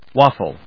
音節waf・fle 発音記号・読み方
/wάfl(米国英語), wˈɔfl(英国英語)/